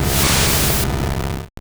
Cri d'Alakazam dans Pokémon Or et Argent.